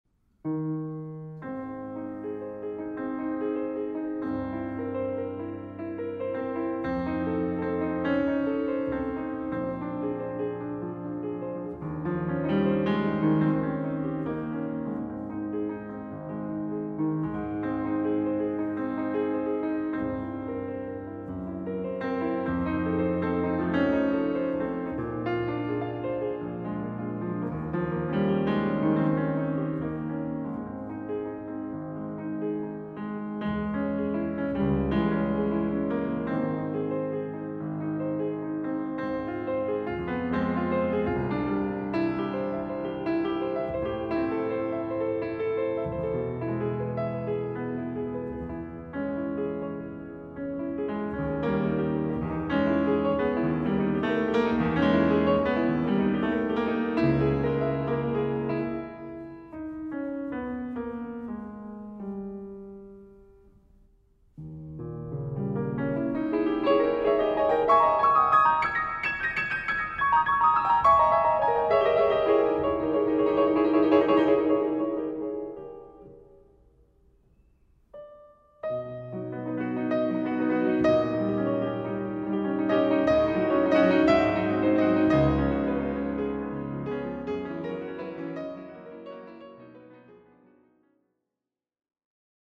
Klavier
Klaviersoli und Lieder